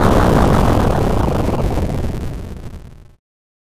描述：复古视频游戏8位爆炸
Tag: 复古 爆炸 8位 视频游戏